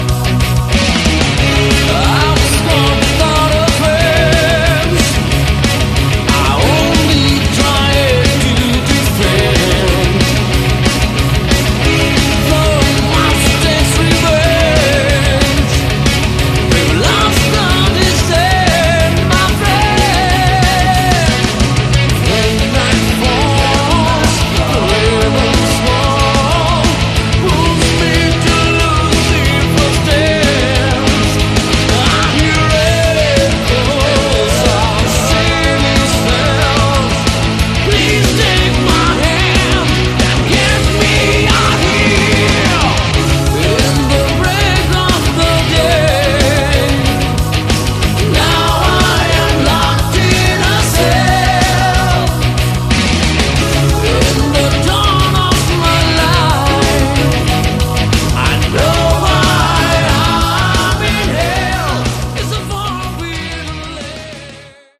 Category: Hard Rock
Vocals
Guitars
Bass
Drums
Keyboards
Backing Vocals